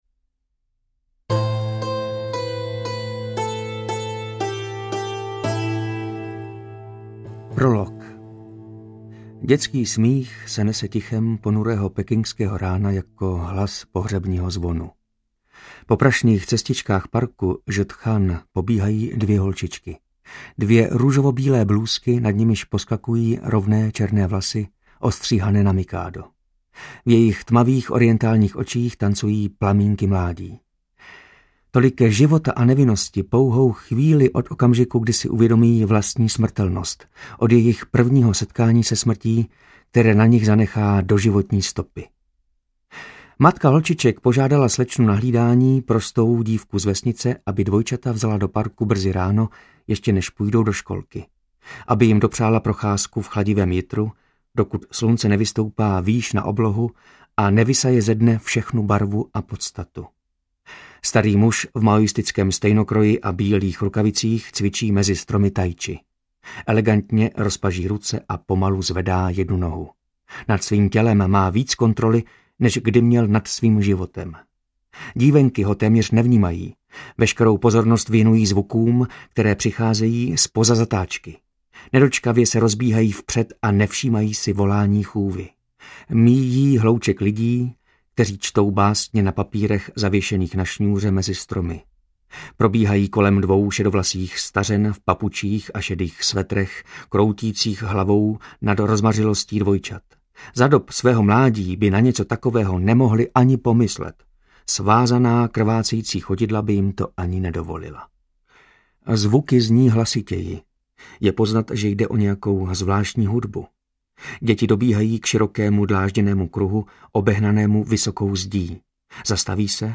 Interpreti:  Martin Myšička, Jana Plodková
AudioKniha ke stažení, 94 x mp3, délka 33 hod. 9 min., velikost 1787,0 MB, česky